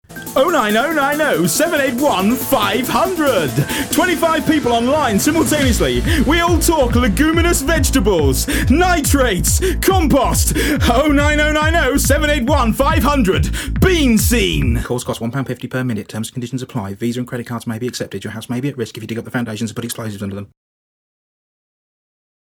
Click here to hear the advert for the adverts in MP3 format!